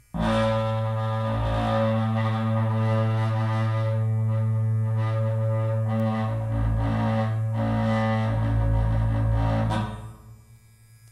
剃刀，打击乐器组 " hi norm hi norm
描述：电动剃须刀，金属棒，低音弦和金属罐。
Tag: 剃须刀 重复 电机 金属 金属加工 发动机